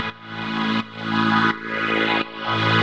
Index of /musicradar/sidechained-samples/170bpm
GnS_Pad-alesis1:2_170-A.wav